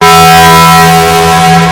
Loud Taco Bell Bong Sound Button: Meme Soundboard Unblocked
Loud Taco Bell Bong